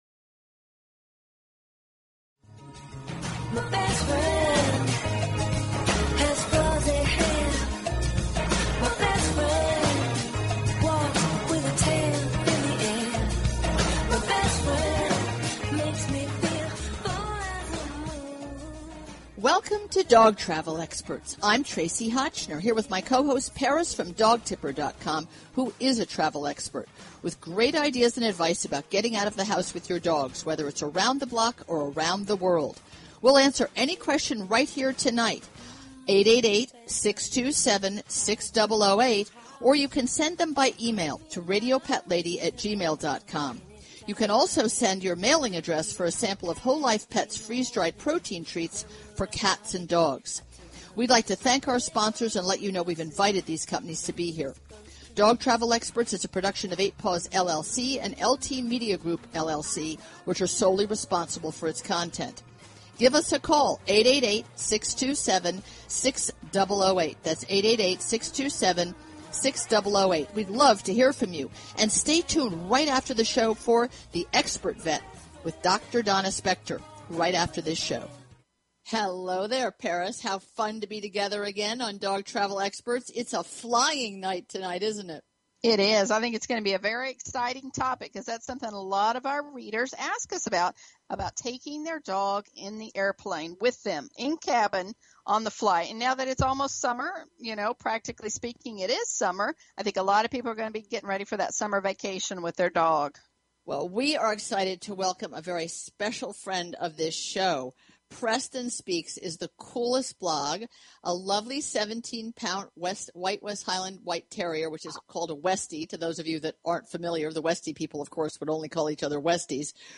Talk Show Episode, Audio Podcast, Dog_Travel_Experts and Courtesy of BBS Radio on , show guests , about , categorized as